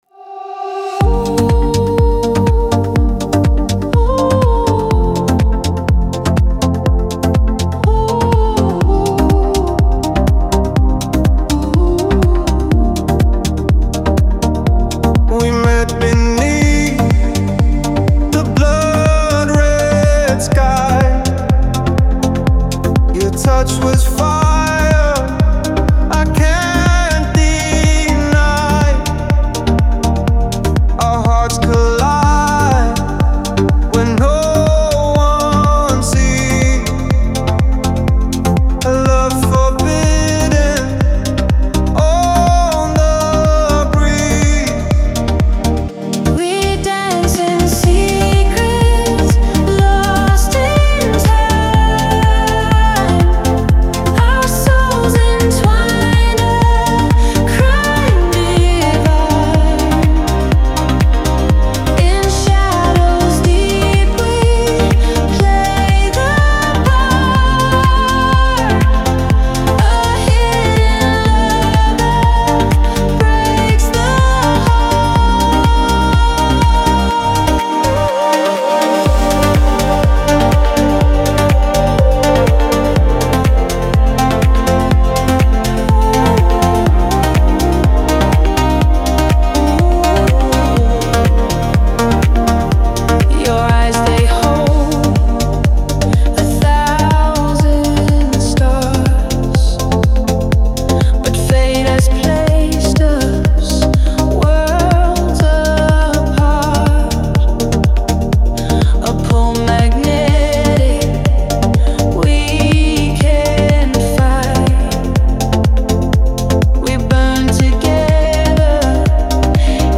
диско , pop
dance